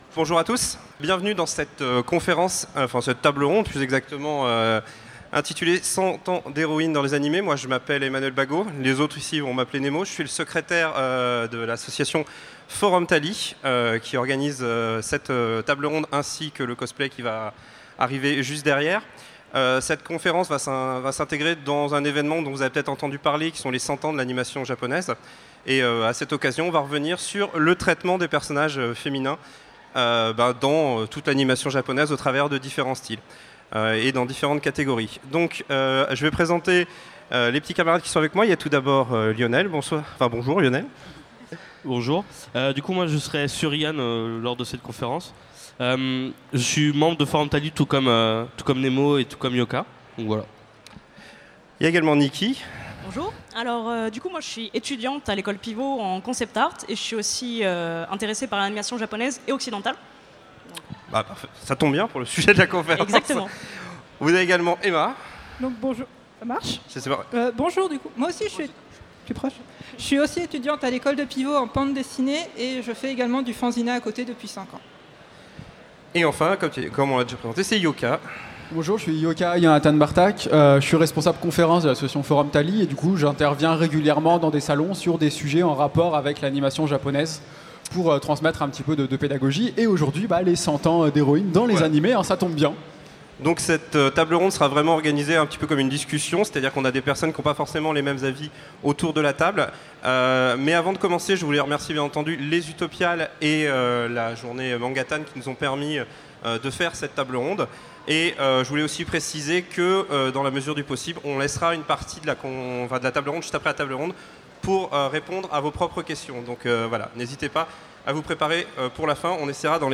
Utopiales 2017 : Conférence 100 ans d’héroïnes dans les animés